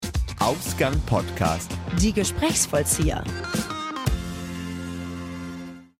markant, dunkel, sonor, souverän
Mittel minus (25-45)
Station Voice